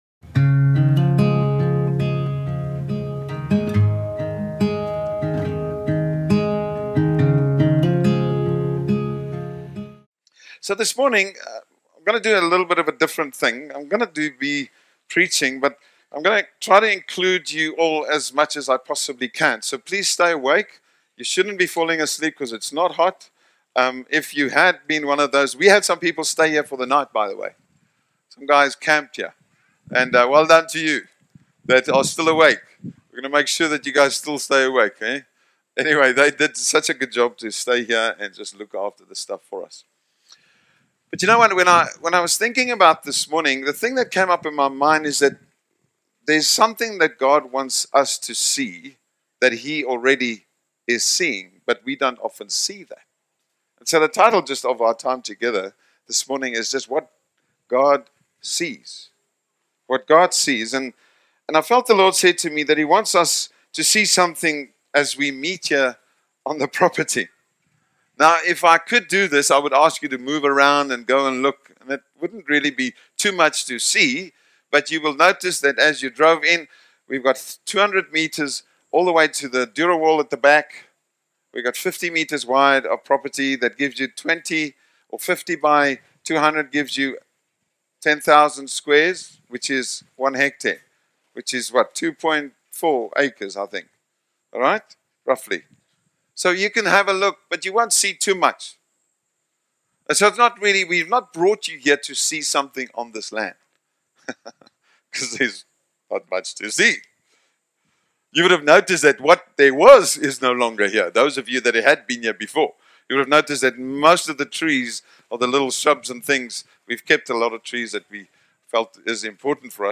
What God Sees || Stand Alone Sermon || 2025